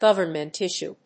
アクセントgóvernment íssue